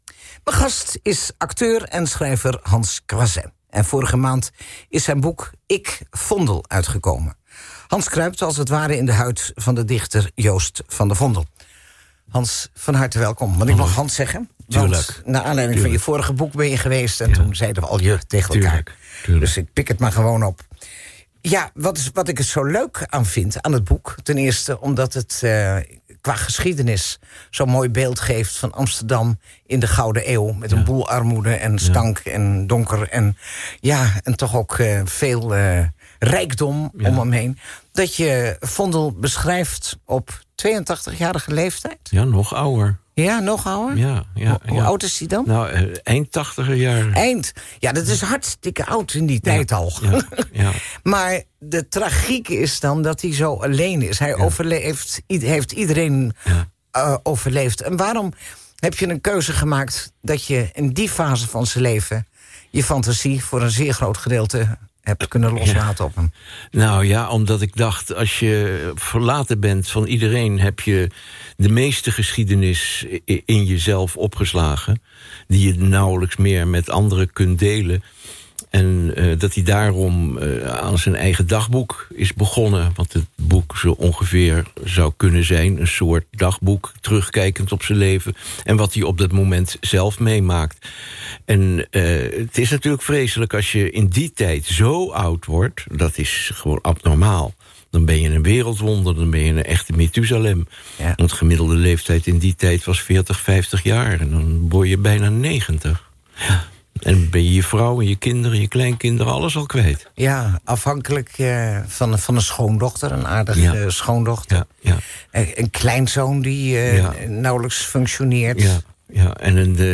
Hans Croiset op 12 december 2017 te gast in de Tinekeshow van Tineke de Nooij op Radio 5 – link